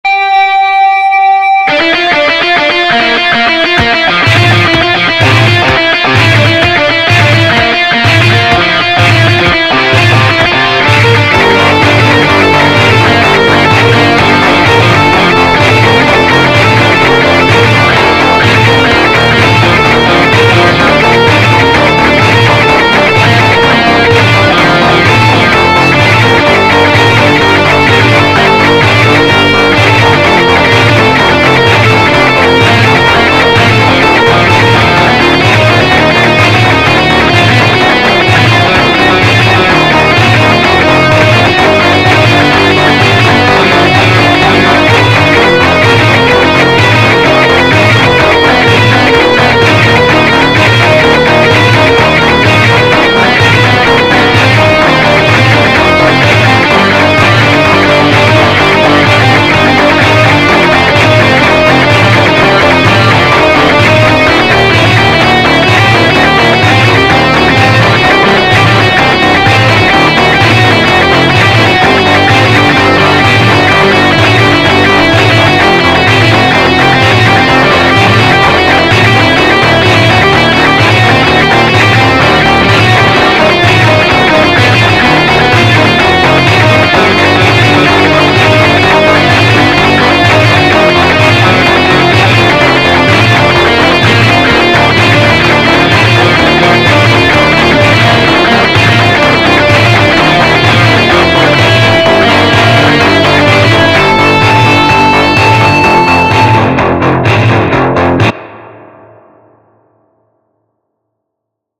Русский Рок 80х